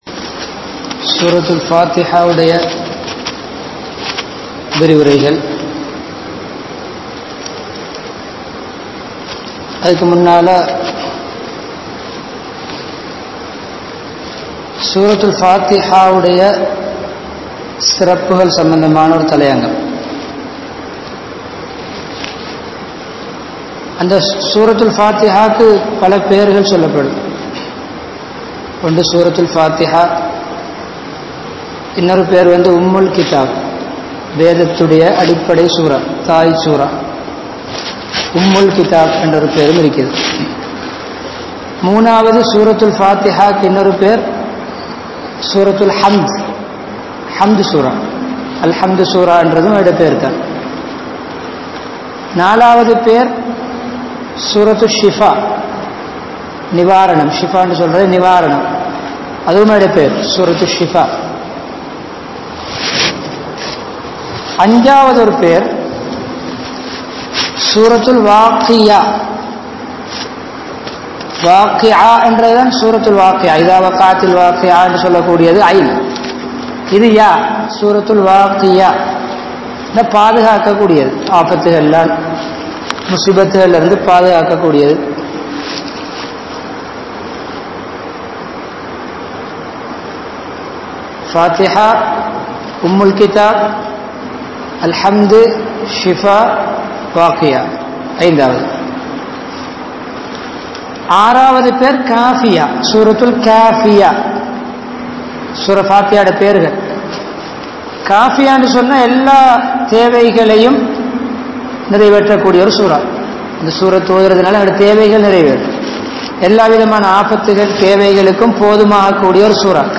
Colombo 15, Mattakuliya, Mutwal Jumua Masjidh